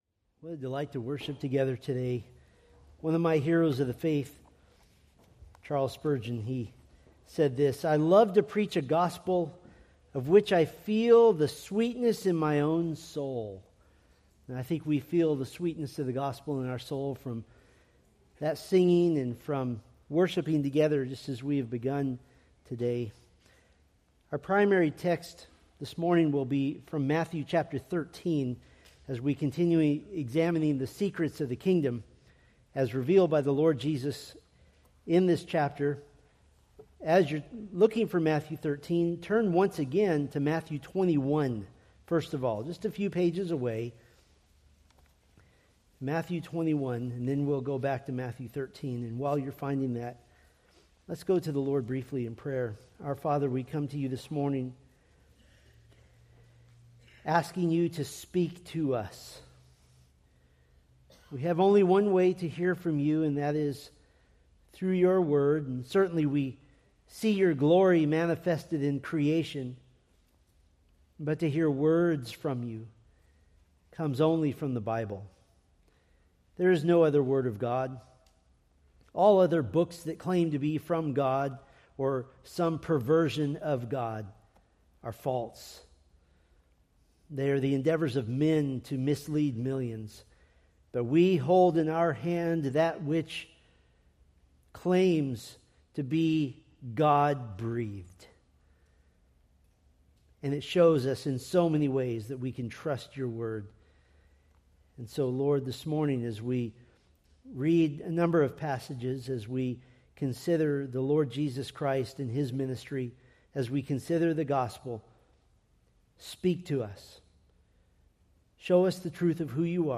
Preached March 29, 2026 from Matthew 13:47-50